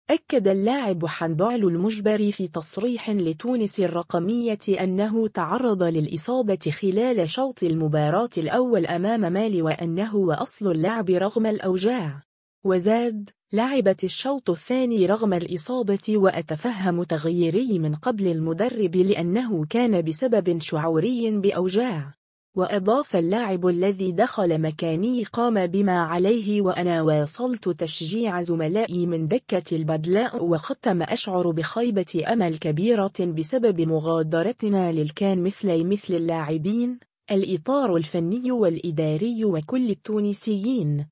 أكّد اللاعب حنبعل المجبري في تصريح لتونس الرقمية أنّه تعرض للإصابة خلال شوط المباراة الأوّل أمام مالي و أنّه واصل اللعب رغم الأوجاع.